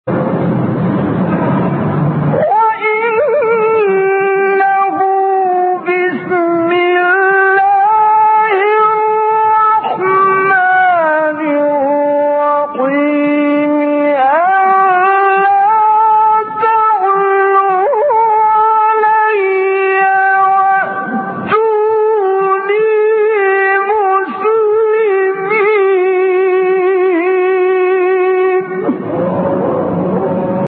گروه شبکه اجتماعی: فرازهای صوتی اجرا شده در مقام حجاز با صوت کامل یوسف البهتیمی ارائه می‌شود.
برچسب ها: خبرگزاری قرآن ، ایکنا ، شبکه اجتماعی ، مقاطع صوتی ، مقام حجاز ، کامل یوسف البهتیمی ، قاری مصری ، تلاوت قرآن ، قرآن ، iqna